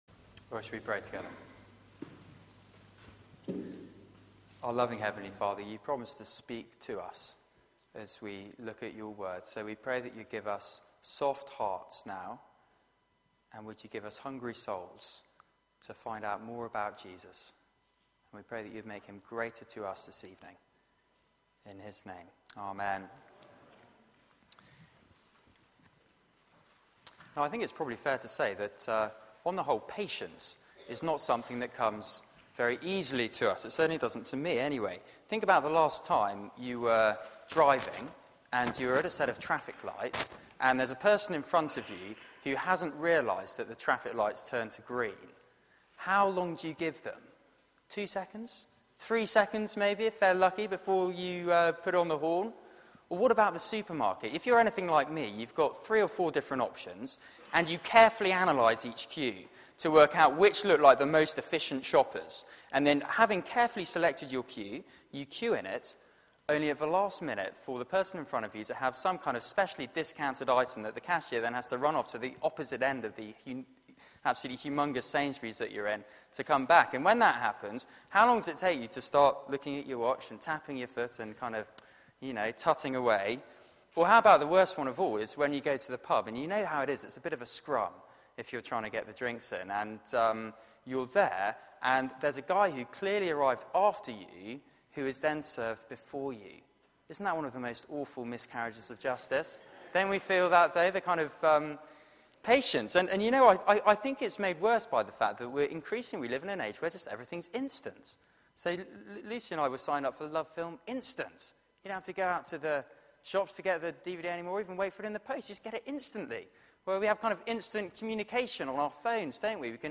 Media for 6:30pm Service on Sun 03rd Nov 2013 18:30
Series: Jesus is better Theme: Feeding on the Bible and why only Jesus saves completely Sermon